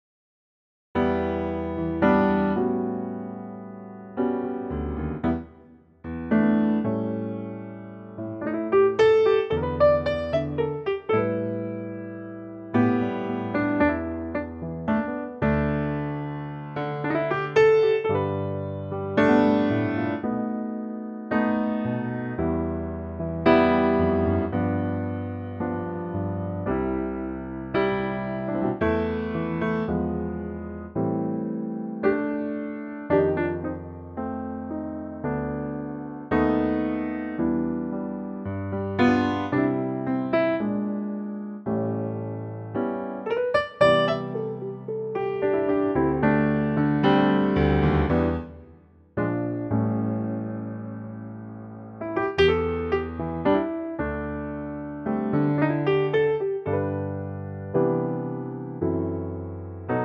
key G
key - G - vocal range - D to E